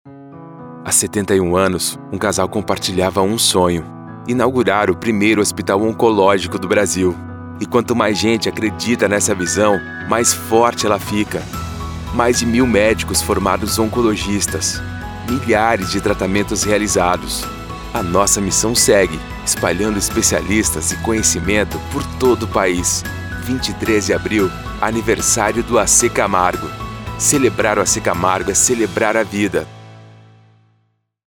VOZES MASCULINAS
Estilos: Padrão Institucional